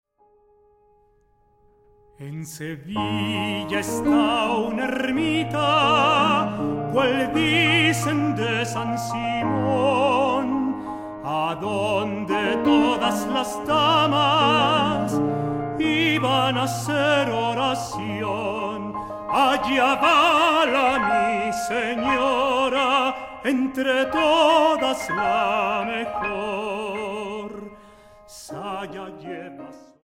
para contralto, piano, armonio y celesta.
mezzosoprano